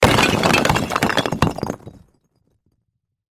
Звуки ударов, разрушения
Звук розсипаної цегляної стіни